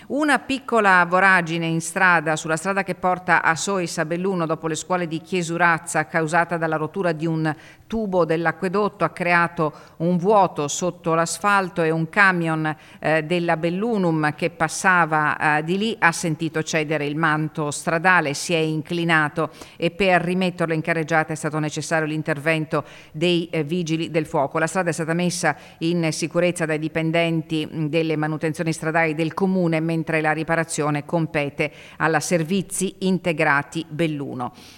** DALLE ANTICIPAZIONI DEL TG DI ANTENNA 3